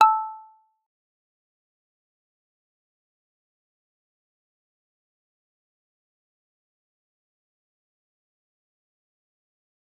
G_Kalimba-A5-f.wav